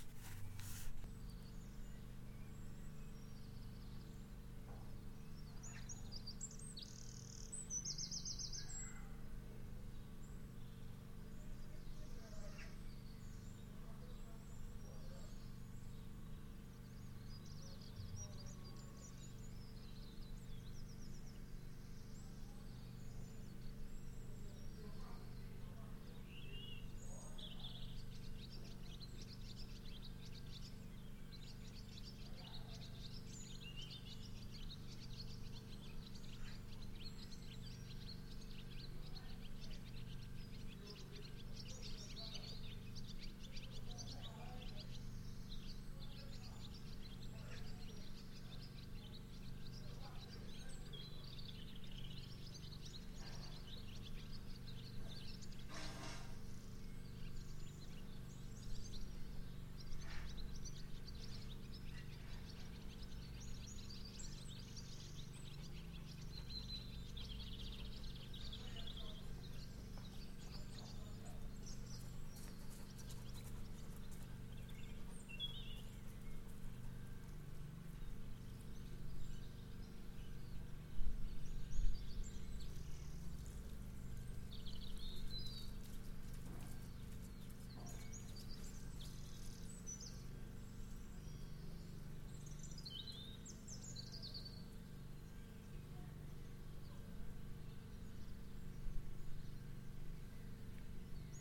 Ambience
Duration - 1m 41s Environment - This was recorded at the entrance of an open semi circular shed which is on a farm with nearby farms surrounding it.
Description - The recorder was picking up all environmental sounds. There are many different sounds from different types of birds in the nearby trees and bushes to a conversation in the next door farm.
So you can also hear the motor of the compressor at a slight distance. Half way through this particular recording there is a dog that walks up close to the recorder and in a few seconds he walks away. You can hear his panting as he approaches you.